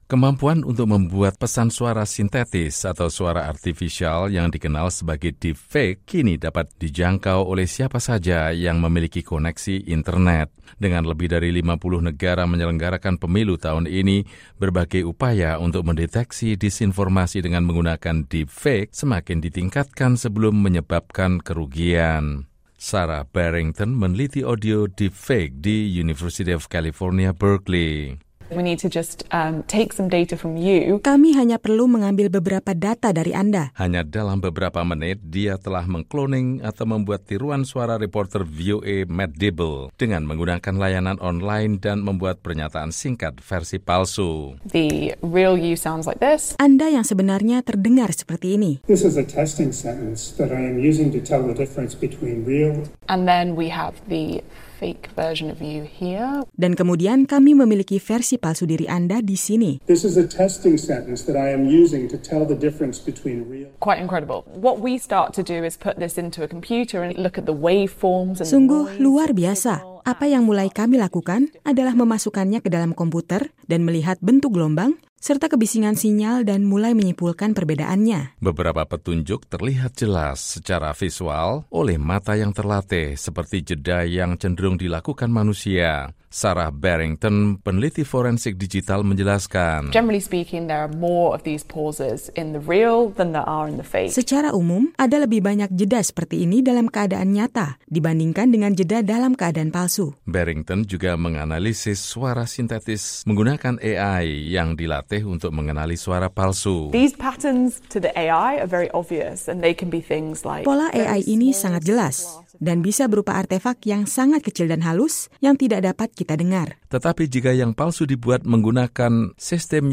Berkeley, CA —
Beberapa petunjuk terlihat jelas secara visual oleh mata yang terlatih, seperti jeda yang cenderung dilakukan manusia.